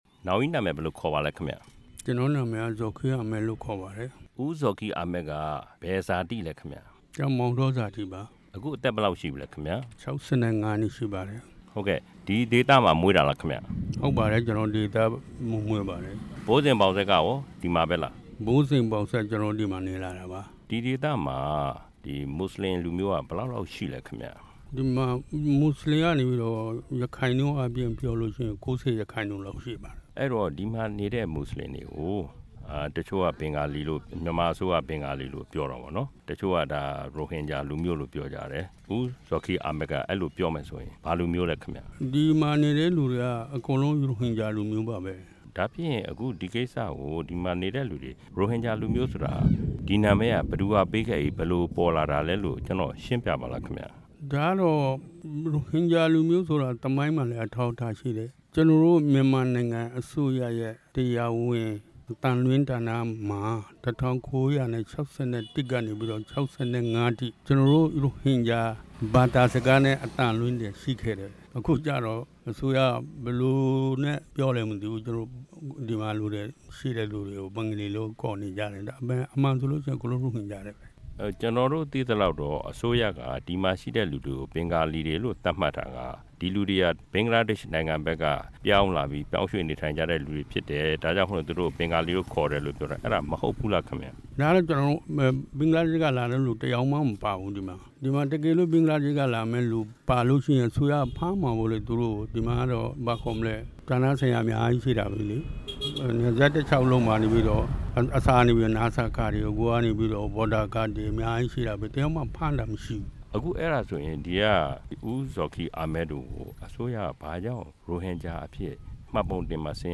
ရန်ကုန်မြို့ နိုင်ငံခြားရေးဝန်ကြီးရုံးမှာ သံတမန်တွေကို ခေါ်ယူကျင်းပခဲ့တဲ့ သတင်းစာရှင်းလင်းပွဲ မှာ နိုင်ငံခြားရေးဝန်ကြီး ဦးဝဏ္ဏမောင်လွင်က အခုလို ပြောကြားခဲ့ပါတယ်။